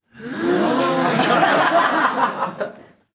Risas y ovaciones del público 1